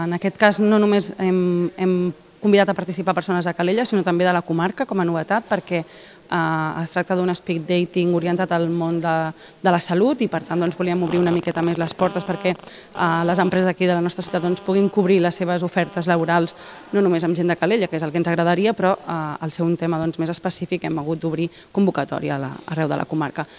Cindy Rando és la tinent d’Alcaldia de Promoció Econòmica de l’Ajuntament de Calella.